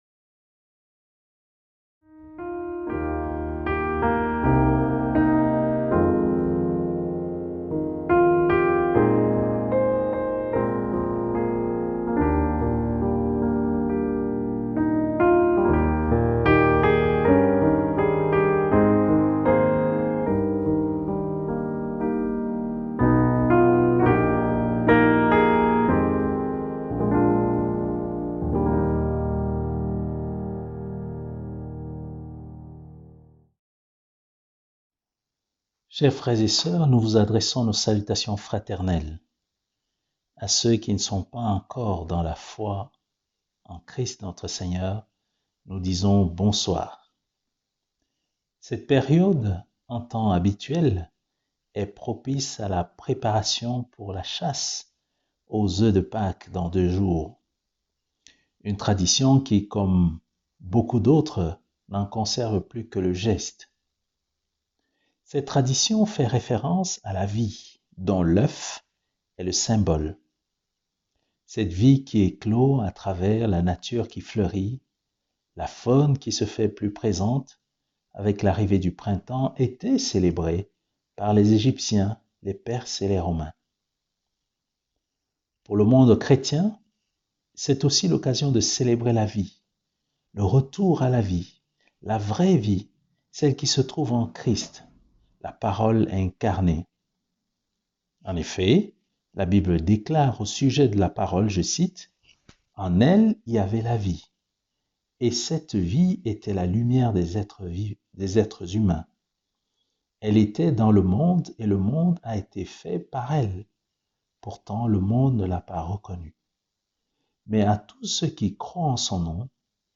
Vendredi saint 10/04/2020. Lectures et méditation sur la croix de Jésus.